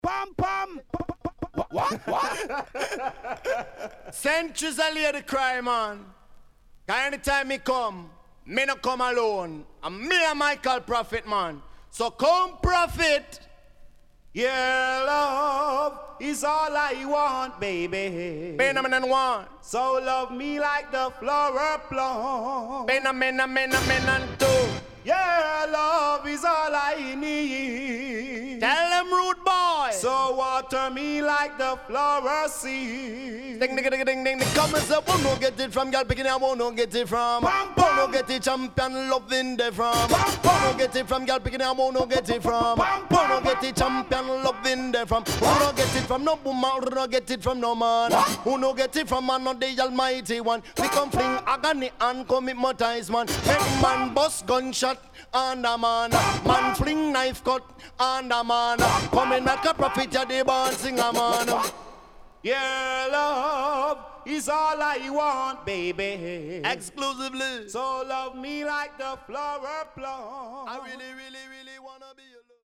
Wicked Combination & Acapella.Good Condition
SIDE A:少しチリノイズ入りますが良好です。